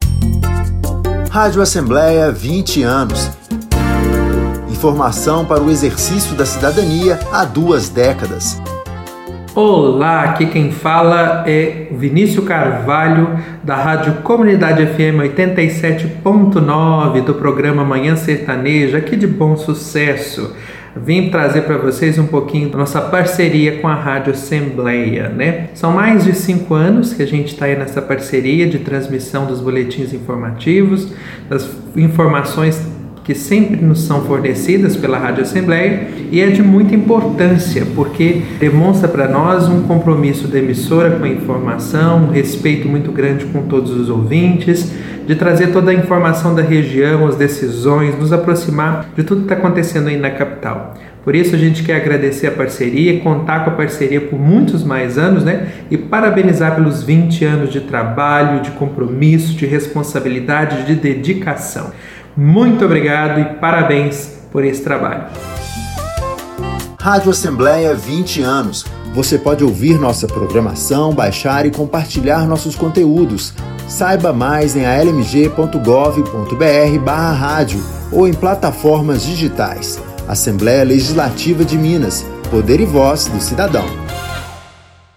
Campanhas educativas e institucionais